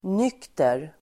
Uttal: [n'yk:ter]